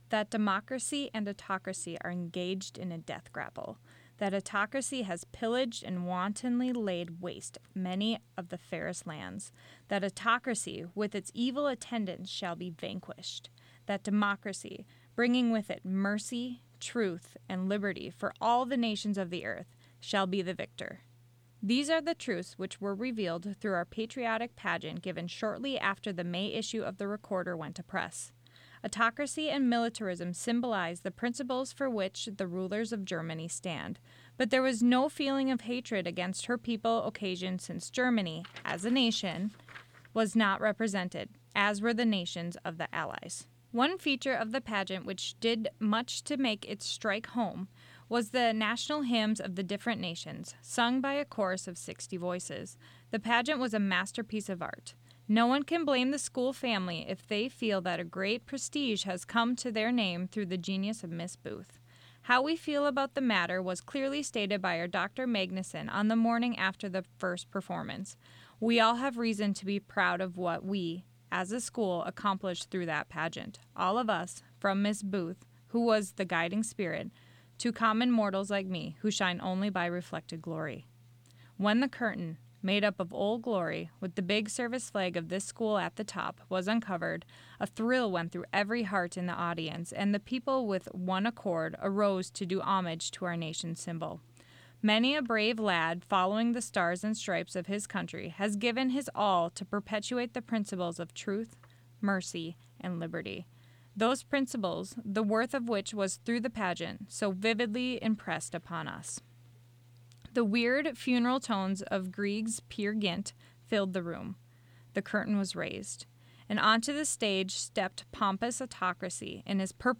Recorded reading of an article entitled "A Patriotic Pageant" by Lydia Becklund published in the July 1918 issue of the Normal School Recorder.